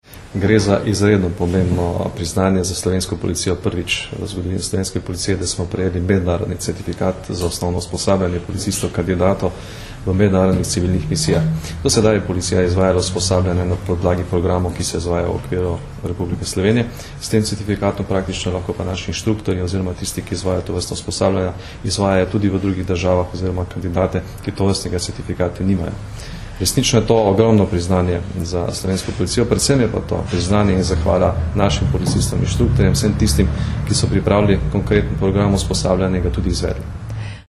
Zvočni posnetek izjave generalnega direktorja policije (mp3)